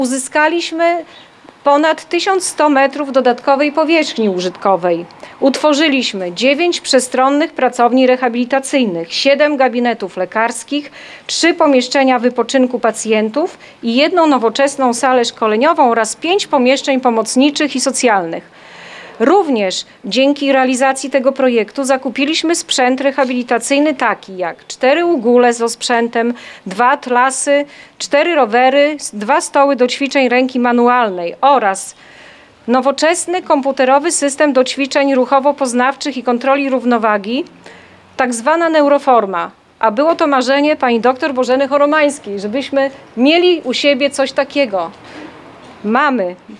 W środę (09.10.19) odbyło się uroczyste otwarcie nowo powstałych pomieszczeń.